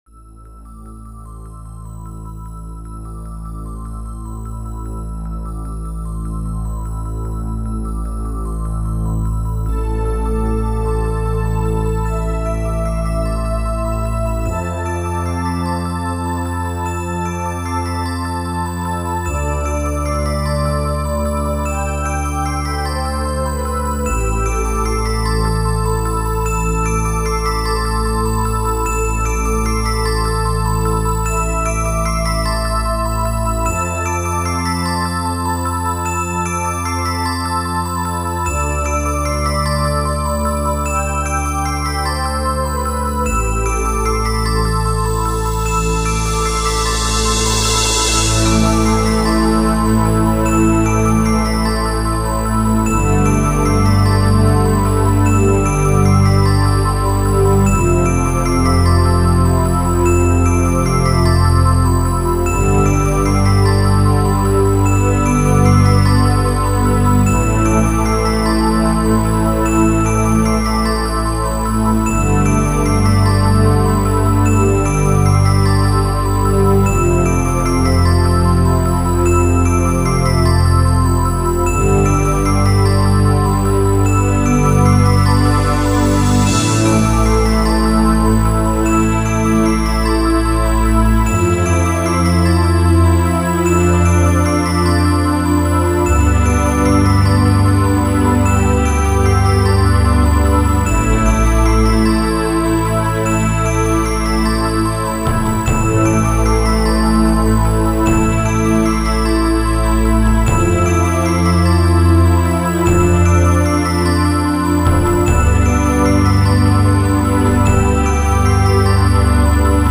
久々になるアルバムは、これまた久々にギャルゲアレンジ。